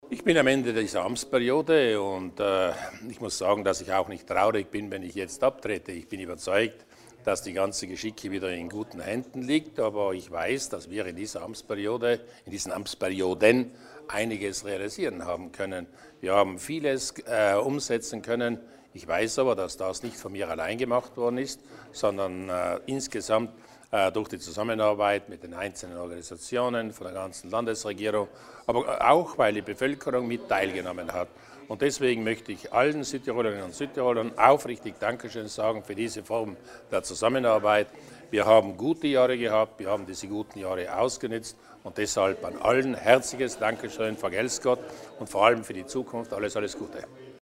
Landehauptmann Arno Kompatscher erläutert seine Prioritäten für die nächste Zukunft